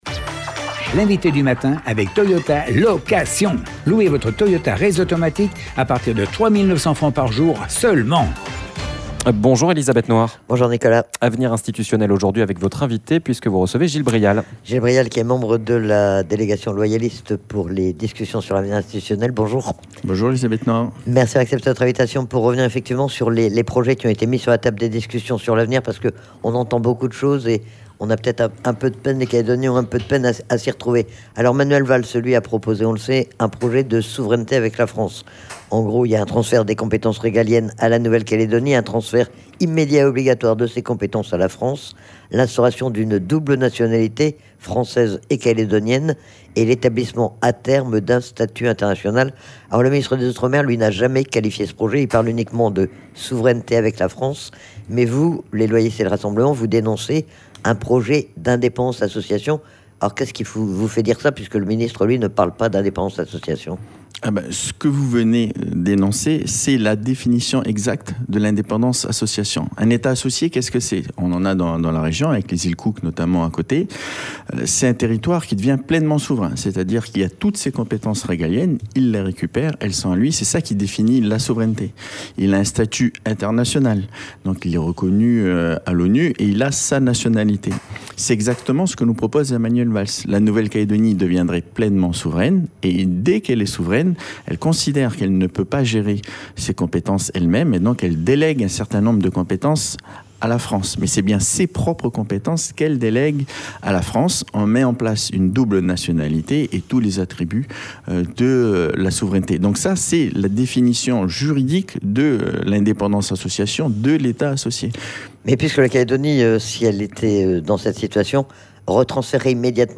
Il est membre de la délégation Loyaliste et il était interrogé sur les projets mis sur la table des discussions sur l'avenir. Quelle est, selon lui, la réalité du projet de souveraineté avec la France de Manuel Valls et du projet de fédéralisme proposé par les Loyalistes et le Rassemblement.